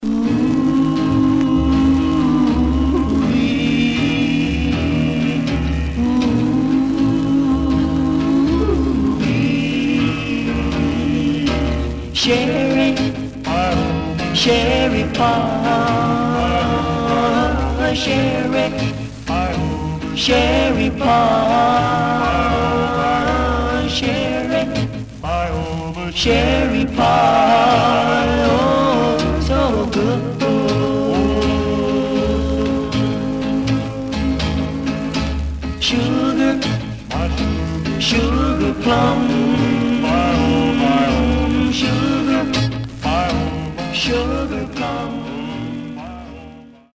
Surface noise/wear
Mono